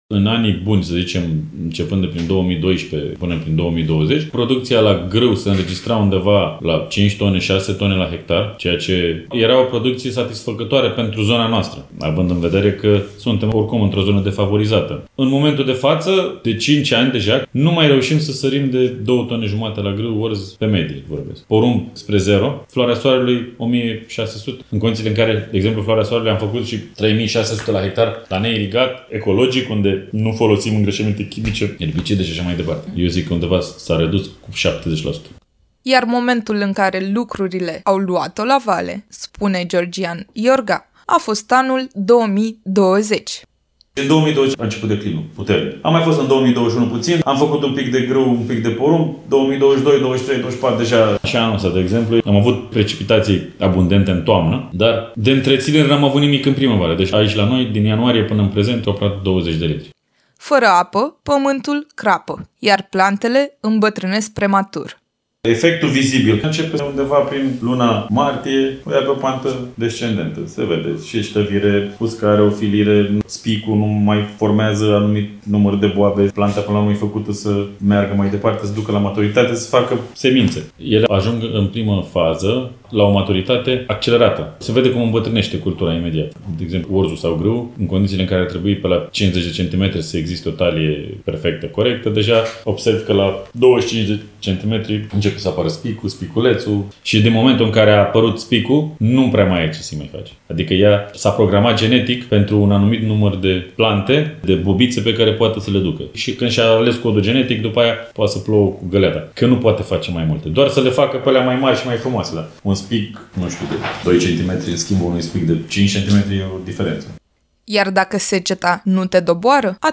Un reportaj din Valea Nucarilor - Știri Constanța - Radio Constanța - Știri Tulcea